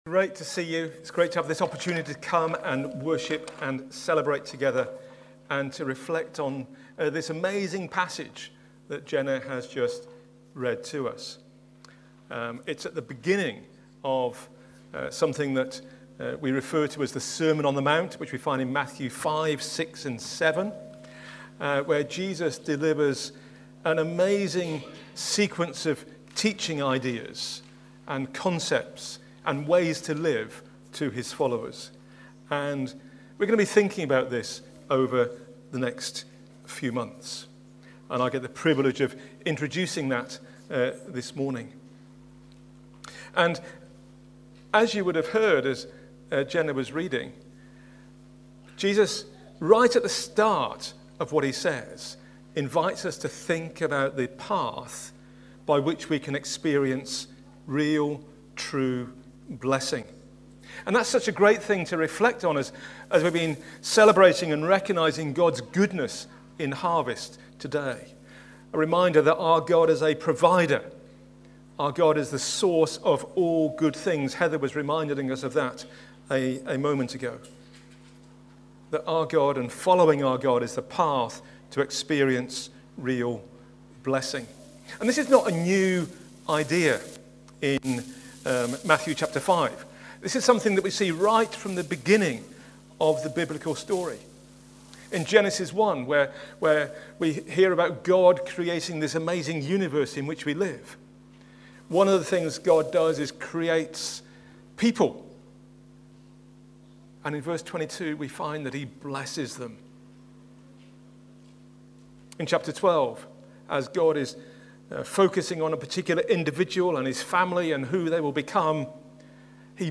A talk from the series "The Sermon on the Mount."